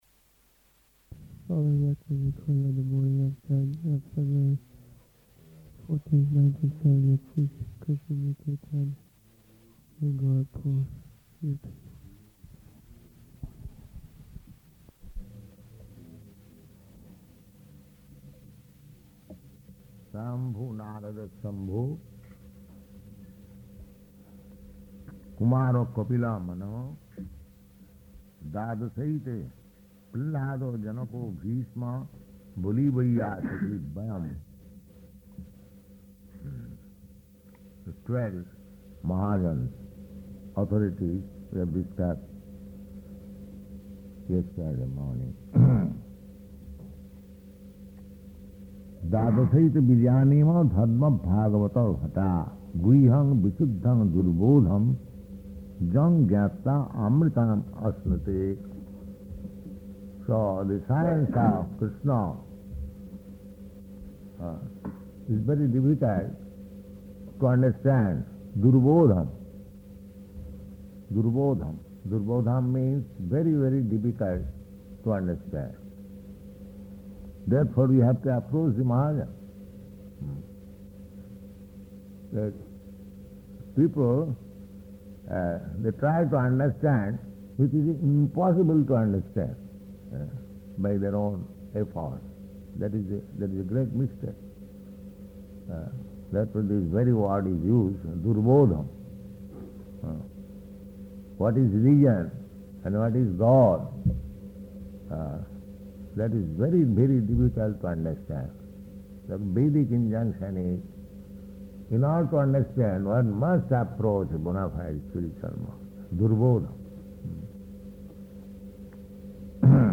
Type: Srimad-Bhagavatam
Location: Gorakphur